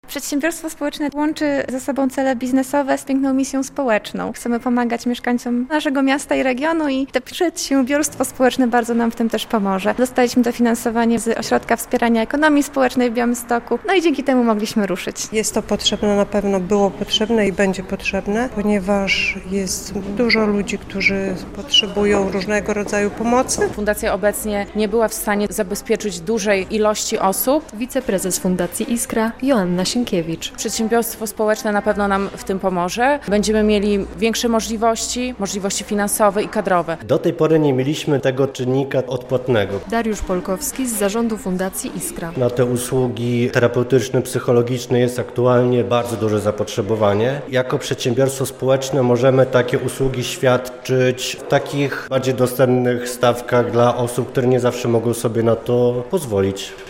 Nowe przedsiębiorstwo społeczne - relacja